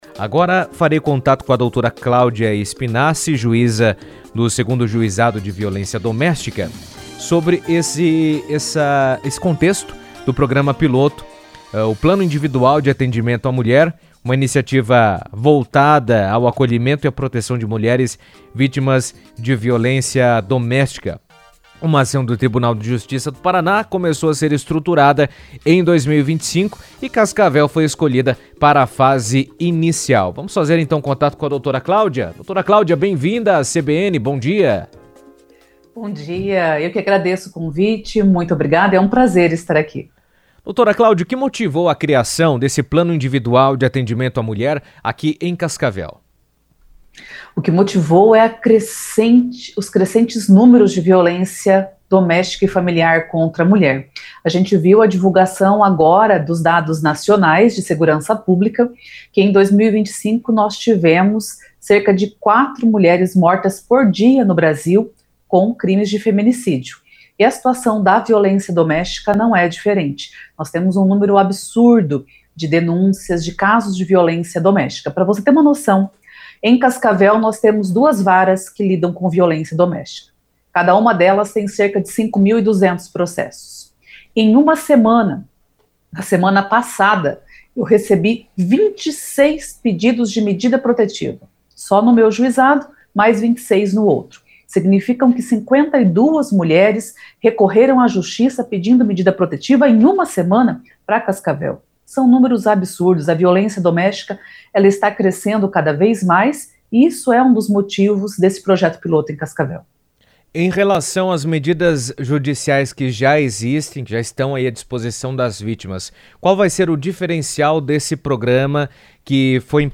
Cascavel está recebendo um projeto piloto que oferece atendimento individualizado a mulheres vítimas de violência, visando acolhimento, orientação e proteção. A juíza do 2º Juizado de Violência Doméstica, Dra. Cláudia Spinassi, comentou sobre a iniciativa em entrevista à CBN, destacando a importância de um atendimento personalizado para garantir segurança e apoio efetivo às vítimas.